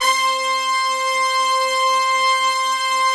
C5 POP BRA.wav